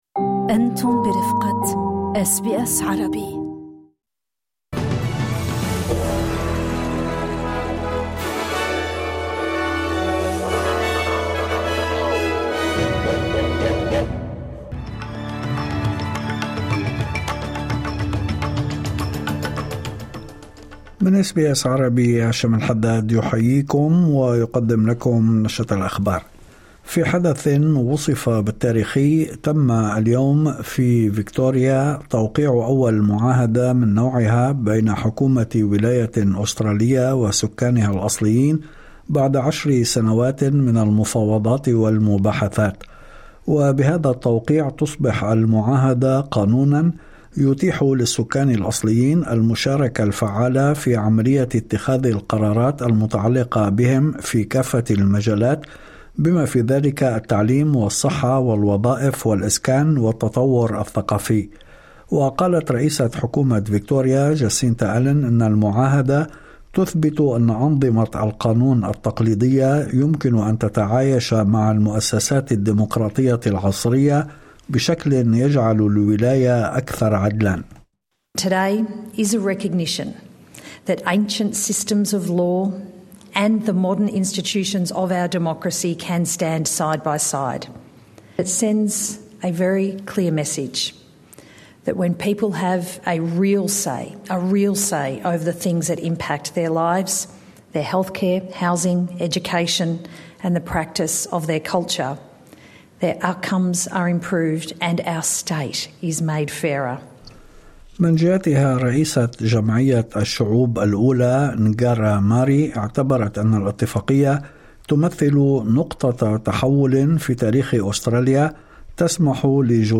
نشرة أخبار الظهيرة 13/11/2025